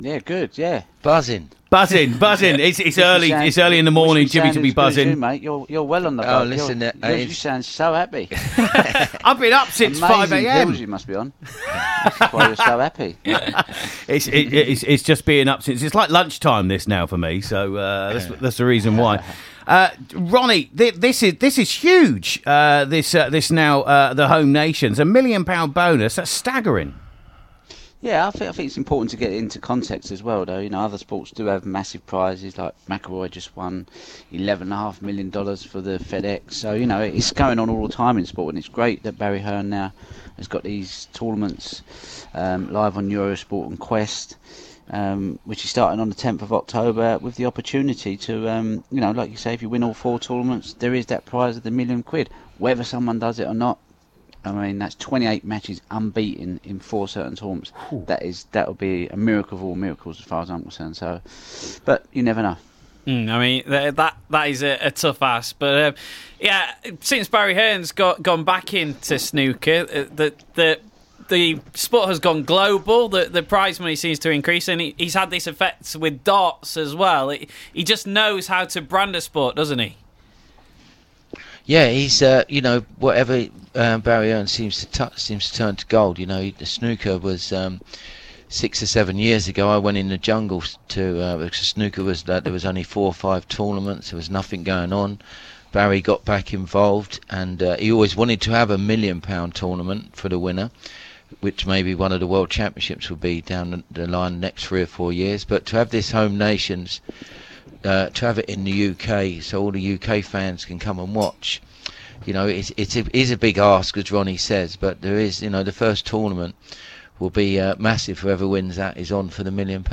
Ronnie O'Sullivan and Jimmy White on Radio Yorkshire